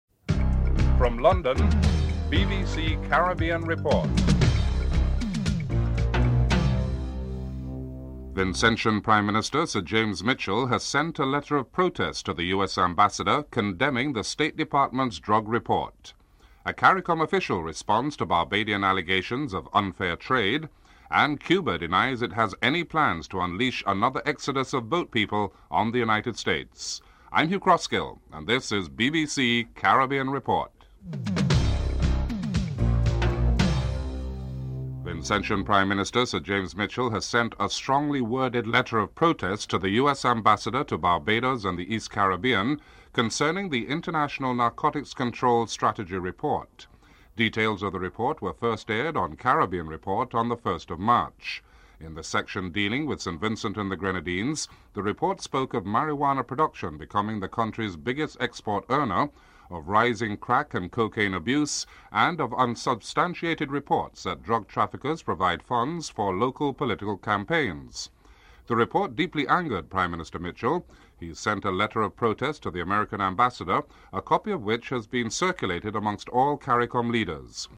St. Vincent's Prime Minister Sir James Mitchell has sent a letter of protest to the US Ambassador Jeanette Hyde condemning the State Department's drug report. Ambassador Hyde speaks on the issue and comments on what would be on the agenda for her meeting with Prime Minister Mitchell.